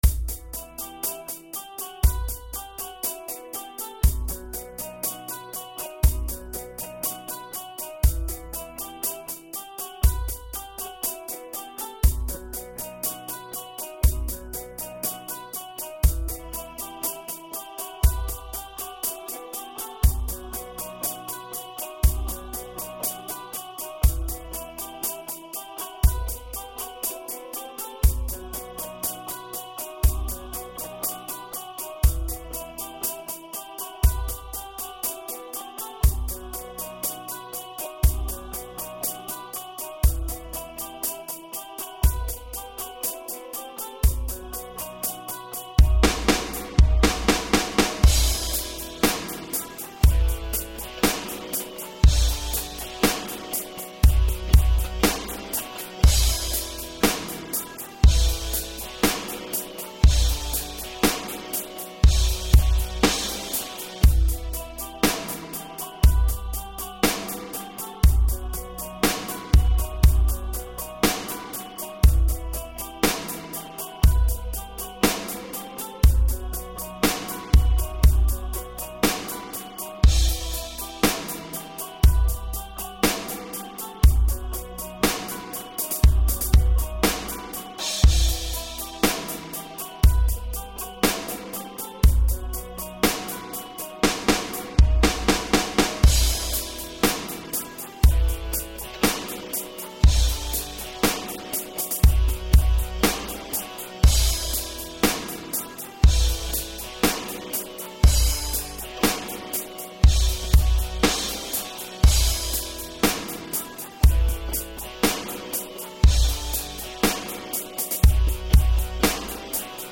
минусовка версия 121268